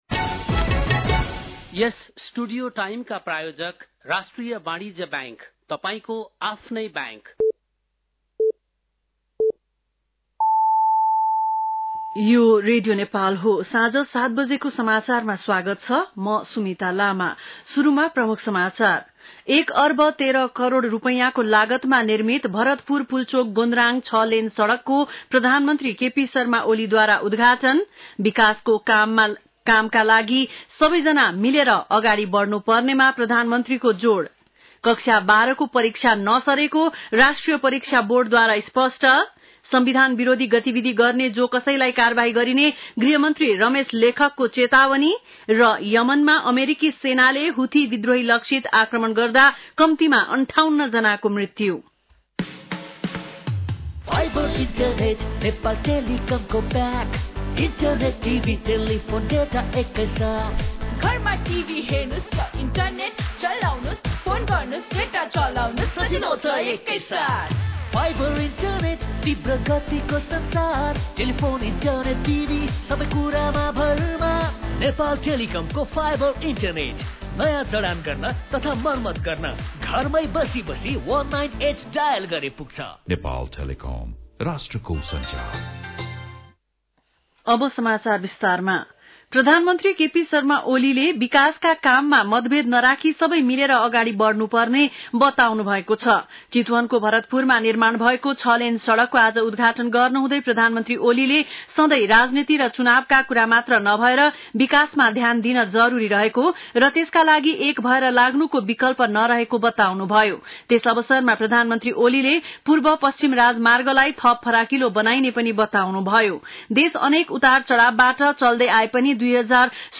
बेलुकी ७ बजेको नेपाली समाचार : ५ वैशाख , २०८२
7-pm-nepali-news-1-05.mp3